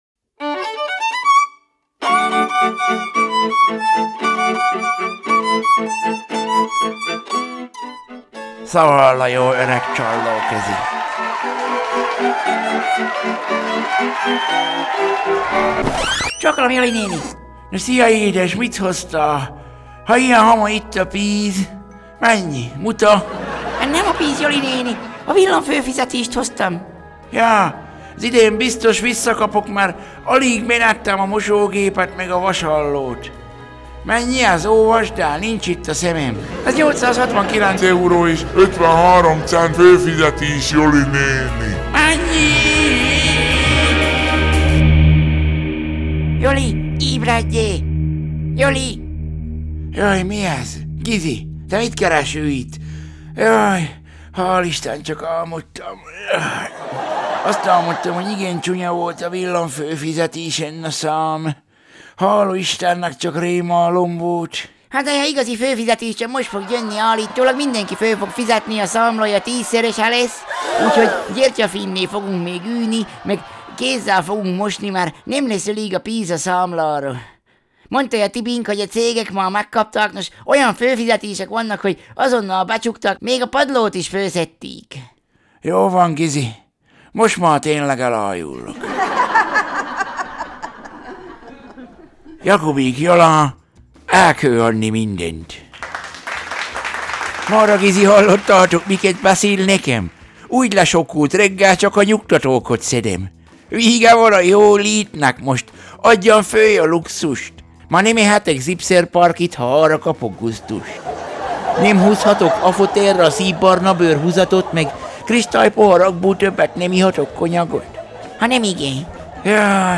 Szaval a jó öreg csallóközi Szaval a jó öreg csallóközi: Eekő anni mindënt!